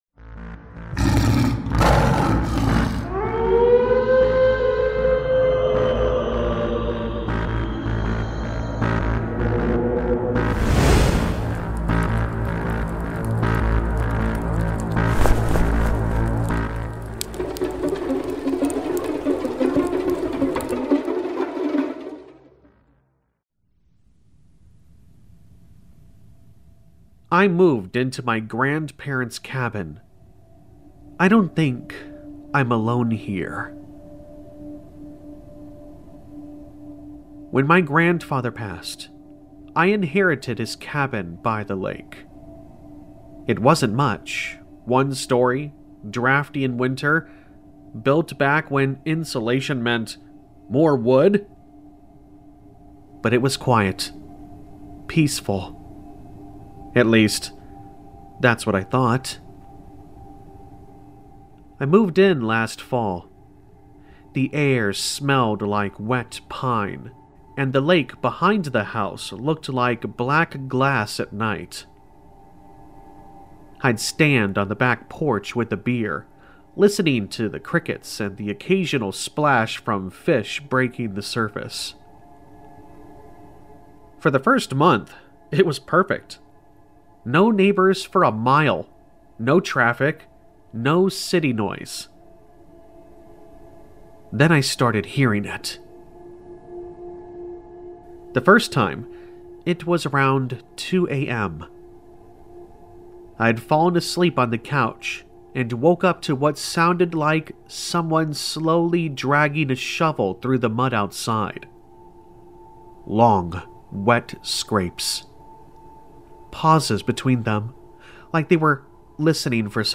In this chilling Creepypasta horror story, a secluded cabin in the deep woods becomes a prison of fear.